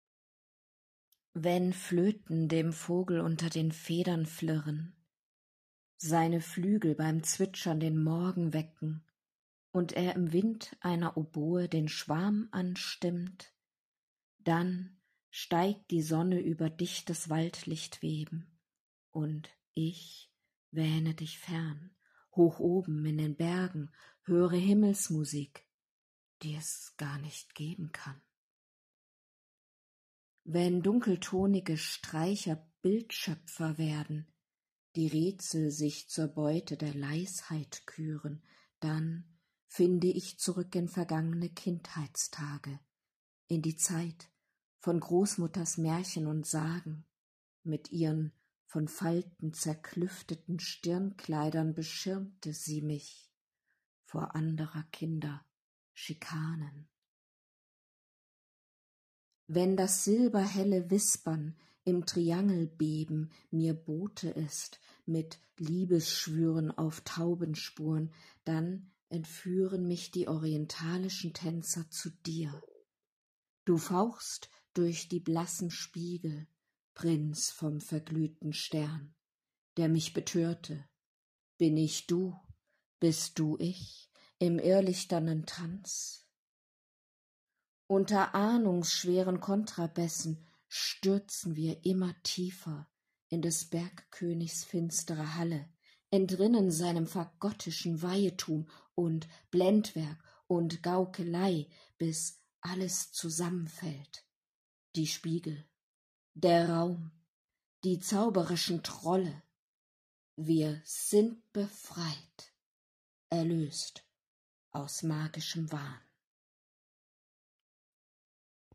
Sprecher: „Norwegische Sagen"